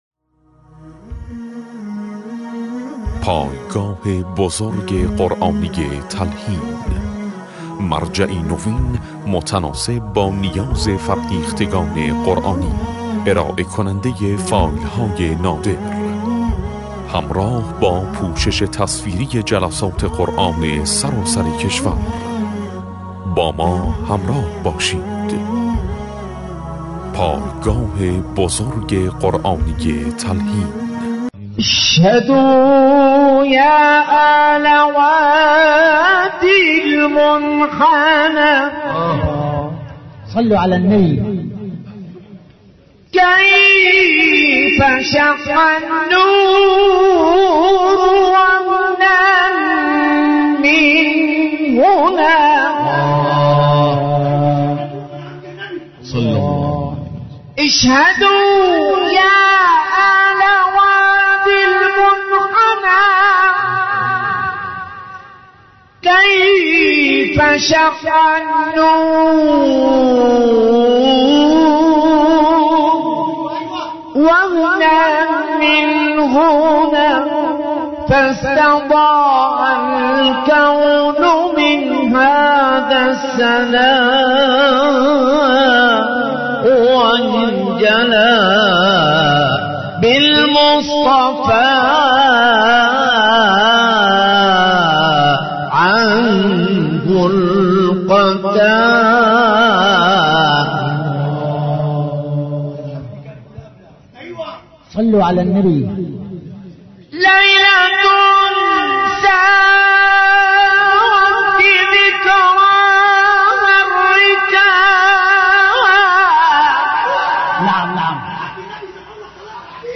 تواشیح شنیدنی
به همراه گروه انشاد دینی
تواشیح